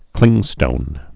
(klĭngstōn)